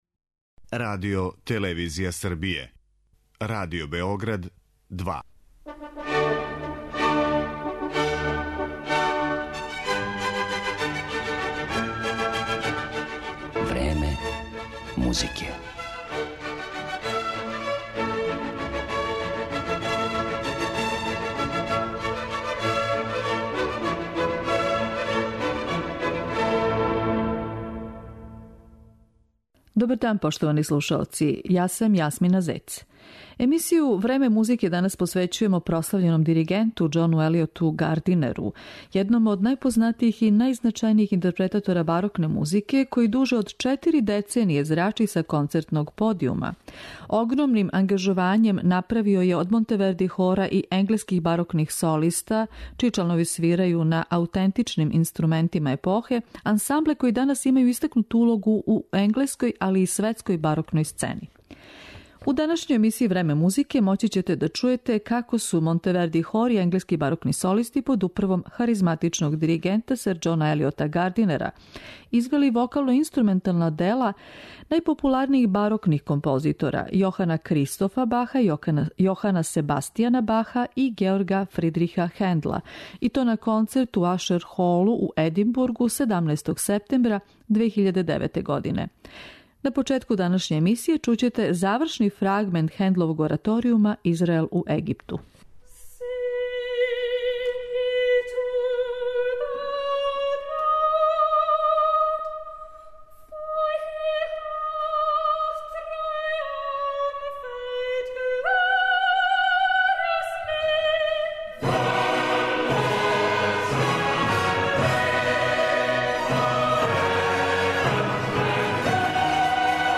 Гардинер је данас водећи ауторитет за тумачење барокне музике.
На концерту одржаном пре неколико година у Енглеској, Гардинер је извео дела Јохана Себастијана Баха, Јохана Кристофа Баха, Георга Фридриха Хендла и Клаудија Монтевердија, која ћемо емитовати у данашњој емисији.